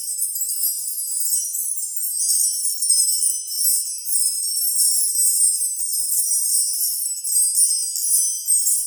magic_sparkle_gem_loop_05.wav